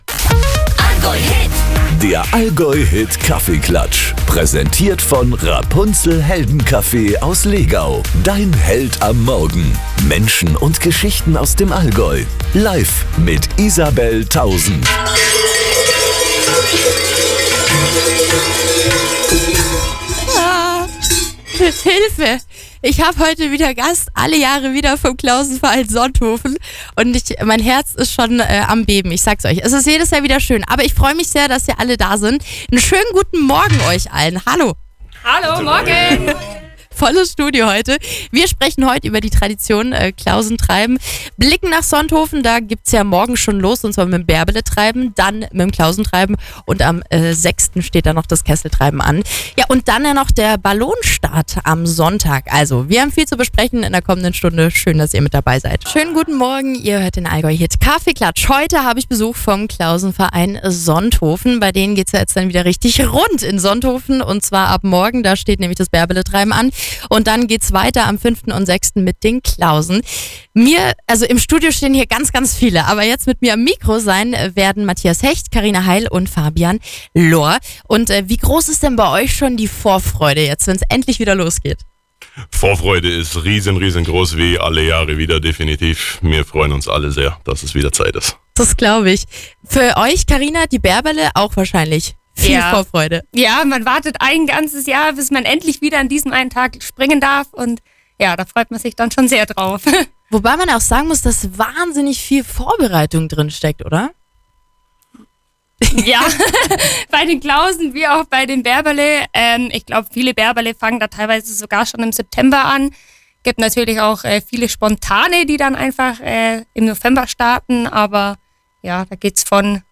Heute war der Klausenverein Sonthofen zu Gast im AllgäuHIT-Kaffeeklatsch. Wir haben über die Tradition, den Verein und das Treiben gesprochen.